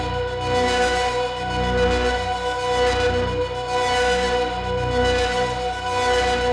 Index of /musicradar/dystopian-drone-samples/Tempo Loops/110bpm
DD_TempoDroneB_110-B.wav